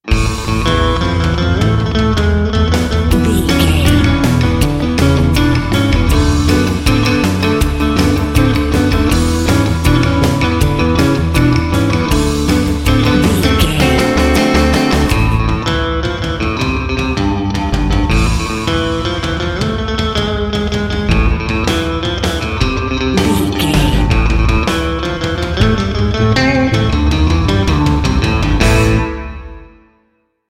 Epic / Action
Fast paced
Aeolian/Minor
groovy
energetic
funky
electric guitar
bass guitar
drums